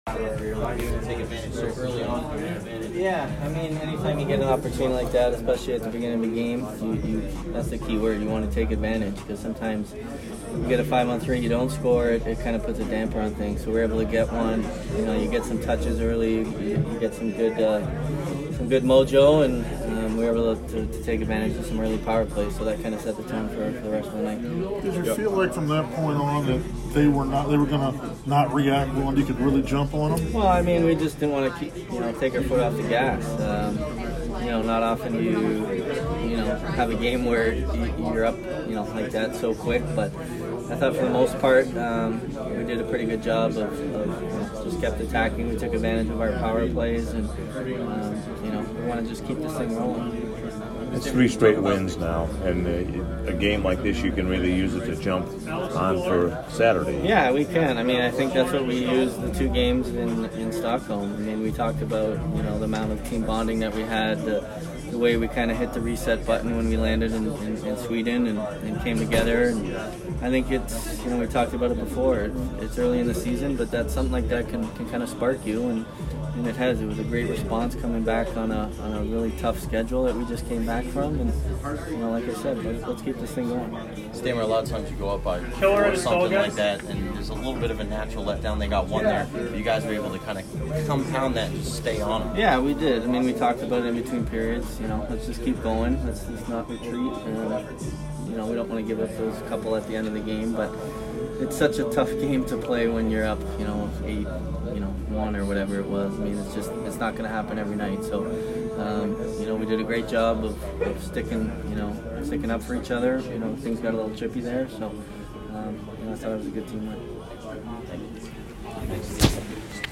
Steven Stamkos post-game 11/14